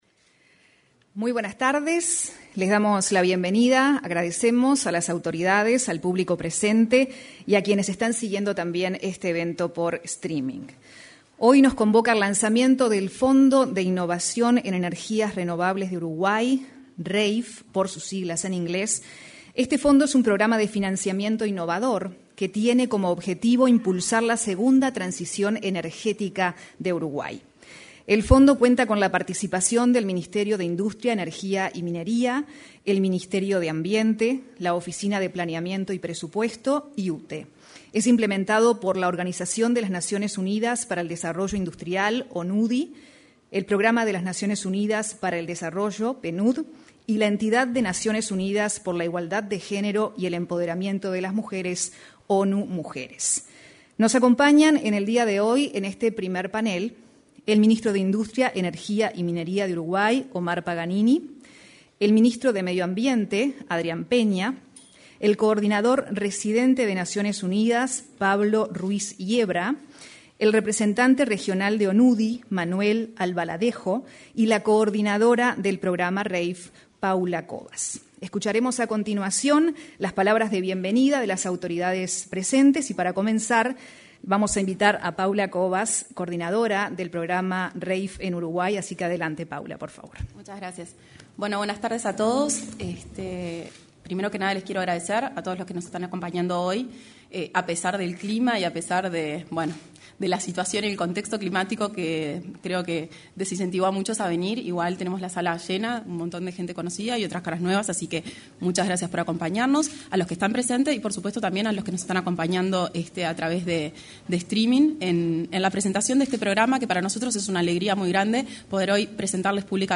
En el salón de actos de Torre Ejecutiva se realizó la presentación del Fondo de Innovación en Energías Renovables, un programa de financiamiento
Participaron los ministros de Industria, Omar Paganini, y de Ambiente, Adrián Peña.